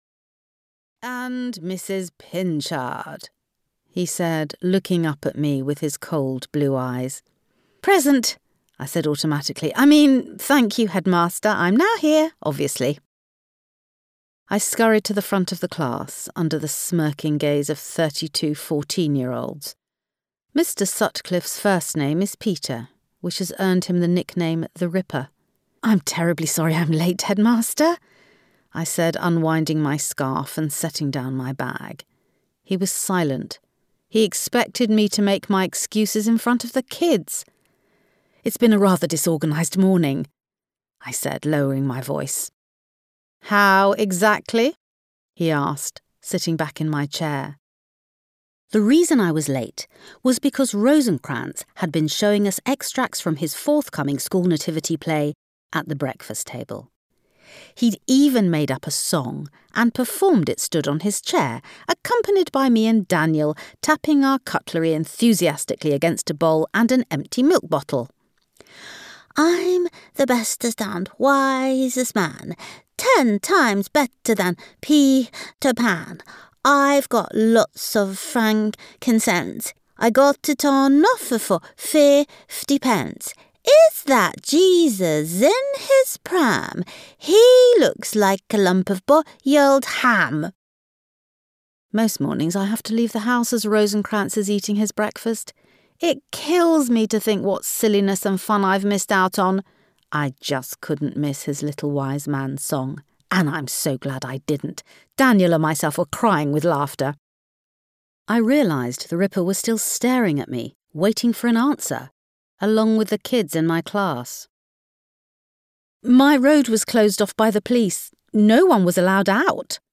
Audio knihaCoco Pinchard’s Must-Have Toy Story
Ukázka z knihy